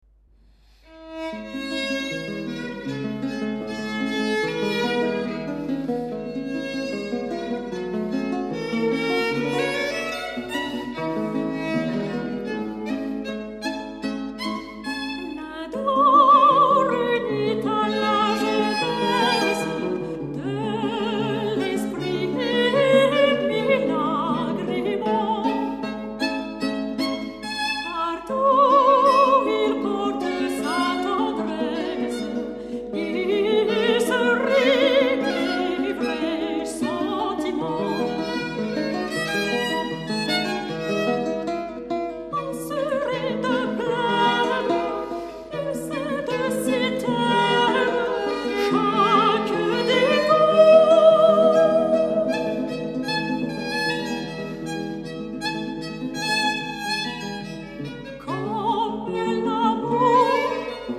這裡收集了他為吉他主奏或伴奏所創作的樂曲，
不管是與人聲、小提琴或長笛的搭配，在今天聽來都相當的新鮮而且悅耳，
這張唱片中使用的三把吉他分別是製作於1790、1800及1810年的名琴，
錄音非常傑出，將這些名琴的共鳴與光澤感完全補抓無遺，
也清楚的表現出各樂器的位置與彼此間美妙的搭配效果。